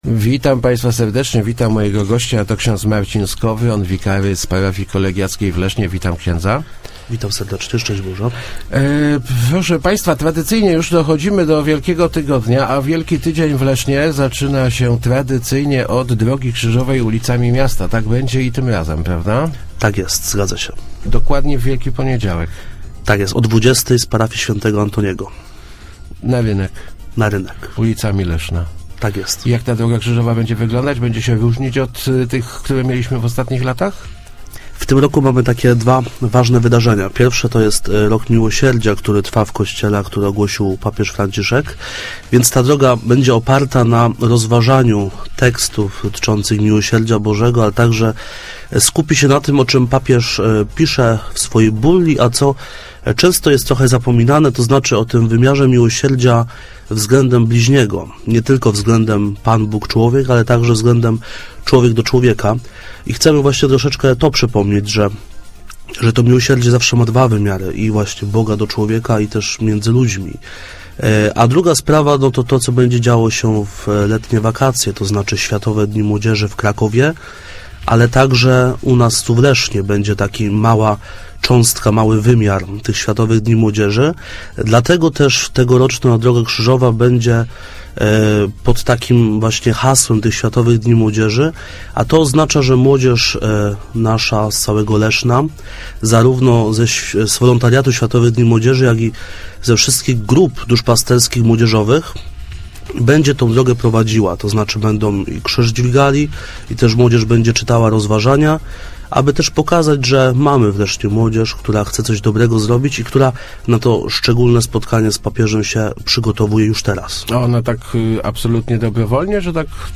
Start arrow Rozmowy Elki arrow Z krzyżem po ulicach